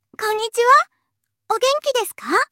2121-8 / japanese-parler-tts-mini like 6